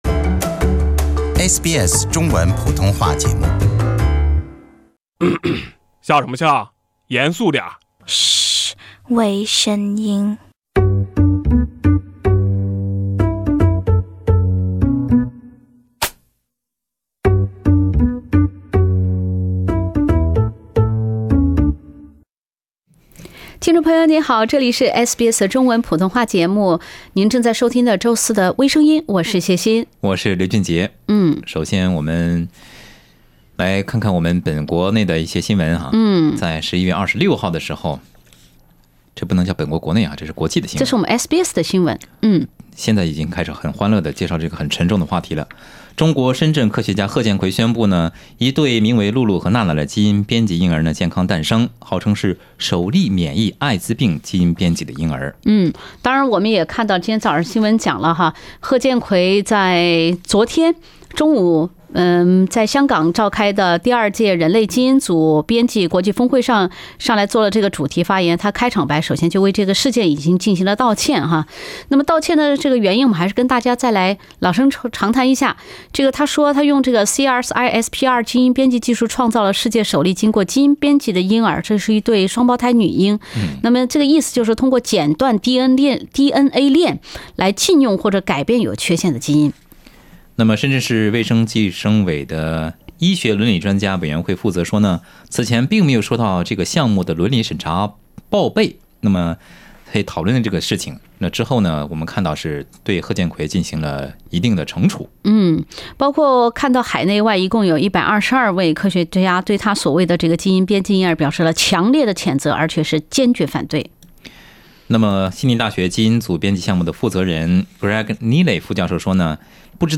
乘客要一扇窗户，空姐手绘窗户引来炸锅评论。另类轻松的播报方式，深入浅出的辛辣点评，包罗万象的最新资讯，倾听全球微声音。